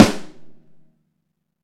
• Roomy Snare Drum Sample A Key 71.wav
Royality free acoustic snare sound tuned to the A note. Loudest frequency: 974Hz
roomy-snare-drum-sample-a-key-71-ggw.wav